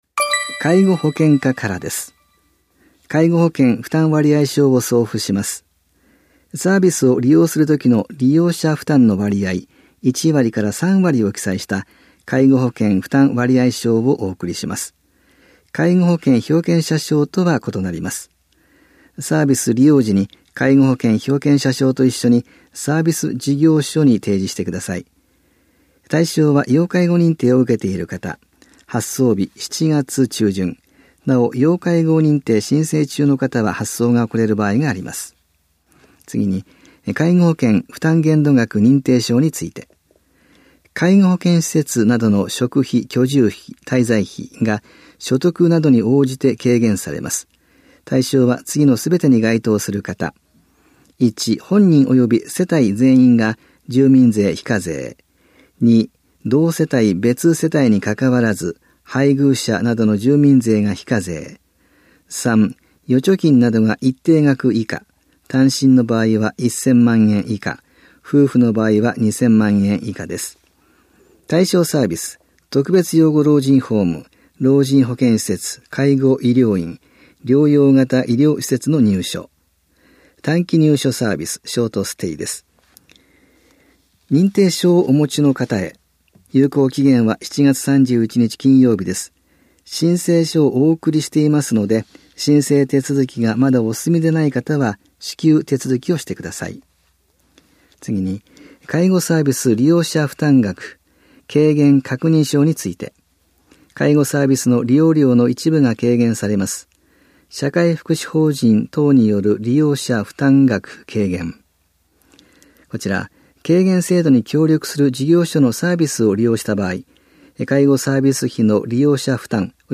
広報「たいとう」令和2年7月5日号の音声読み上げデータです。